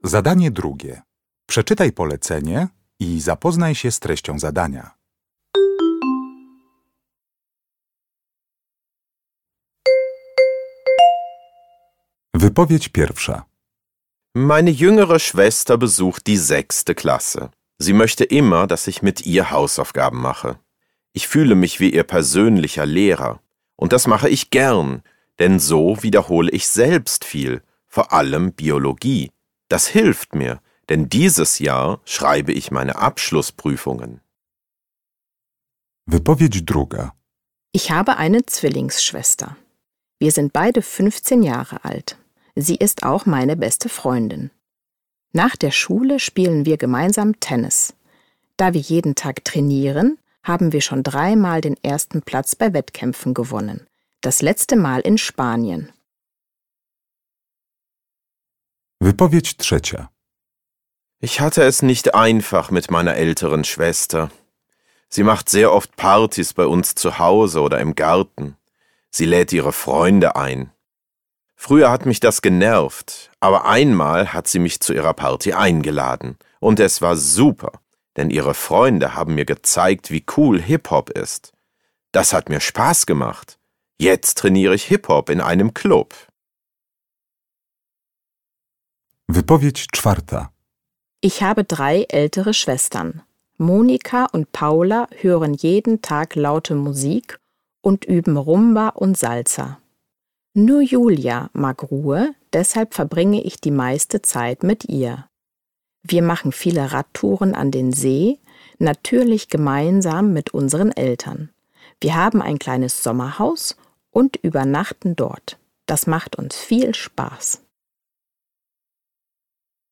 Uruchamiając odtwarzacz z oryginalnym nagraniem CKE usłyszysz dwukrotnie cztery wypowiedzi na temat relacji nastolatków ze swoim rodzeństwem.